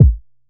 Murda Kick (B).wav